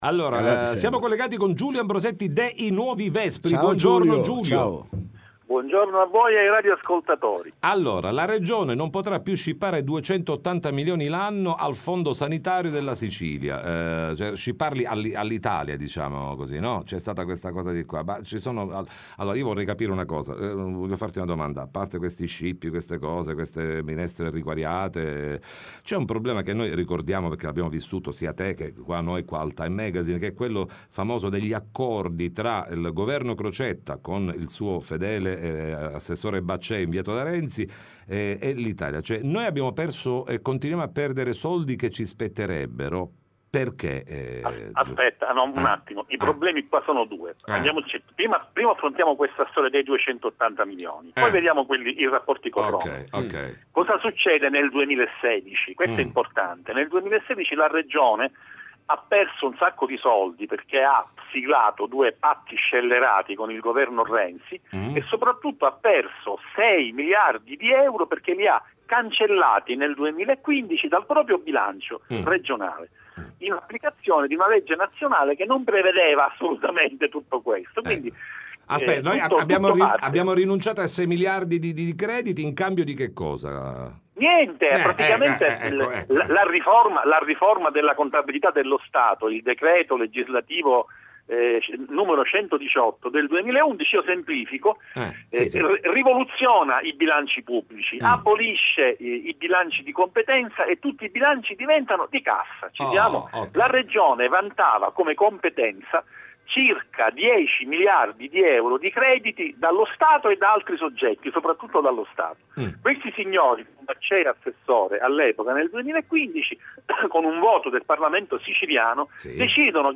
TM Intervista